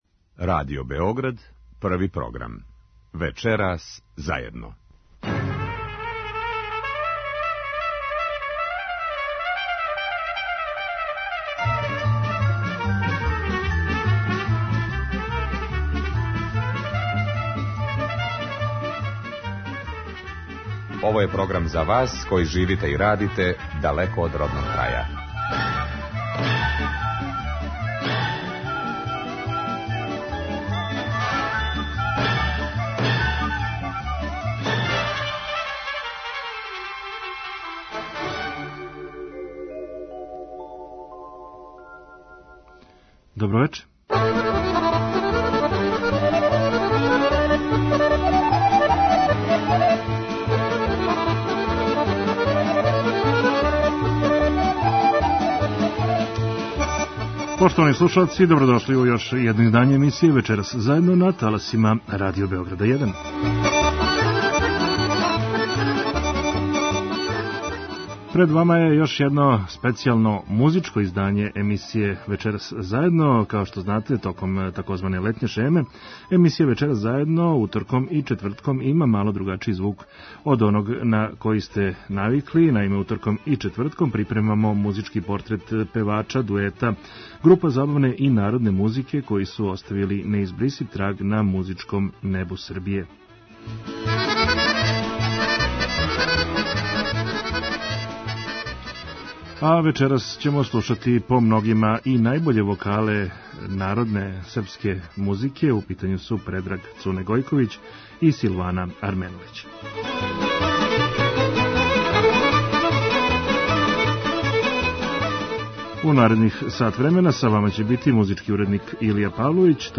Тих дана припремамо музички портрет певача, дуета, група забавне и народне музике који су оставили неизбрисив траг на музичком небу Србије. Многа извођења која ћете чути чувају се као трајни записи у богатој Фонотеци Радио Београда.